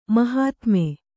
♪ mahātme